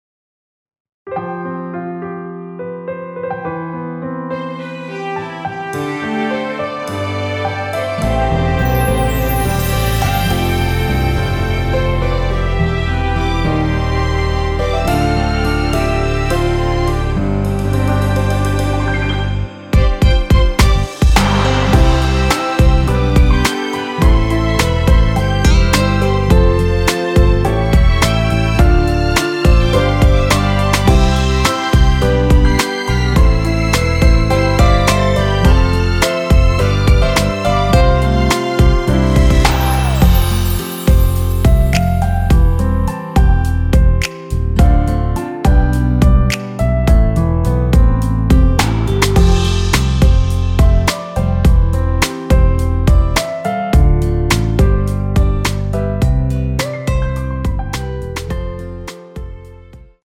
대부분의 남성분이 부르실수 있는 키로 제작하였습니다.(미리듣기 확인)
원키에서(-8)내린 MR입니다.
앞부분30초, 뒷부분30초씩 편집해서 올려 드리고 있습니다.
중간에 음이 끈어지고 다시 나오는 이유는